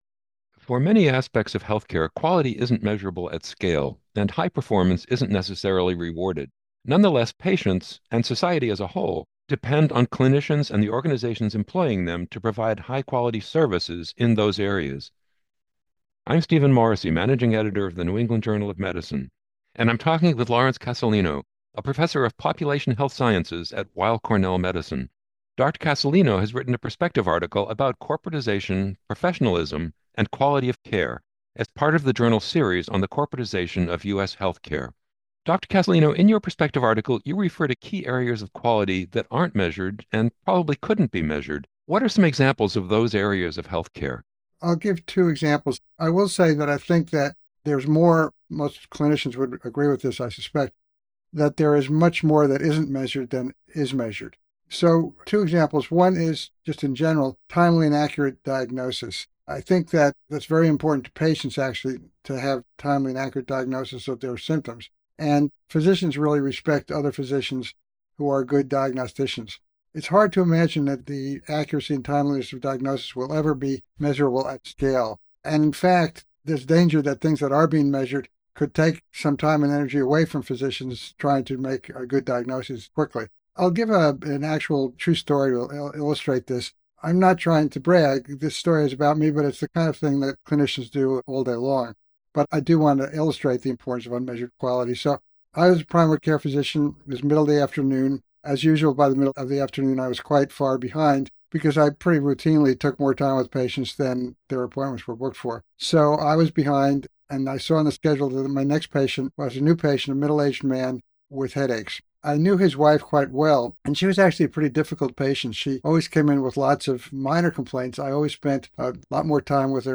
NEJM Interviews NEJM Interview